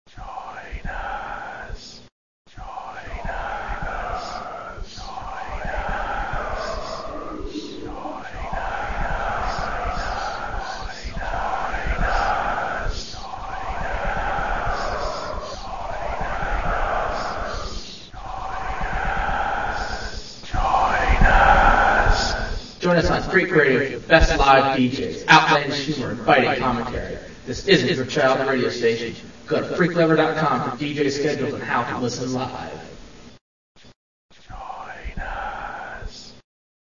Radio Ad for Freak Radio